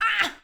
SFX_Battle_Vesna_Defense_02.wav